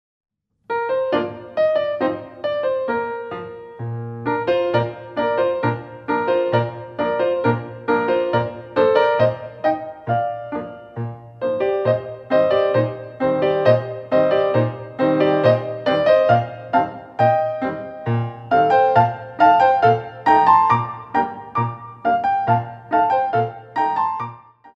5 Battements Tendus Accents In & Out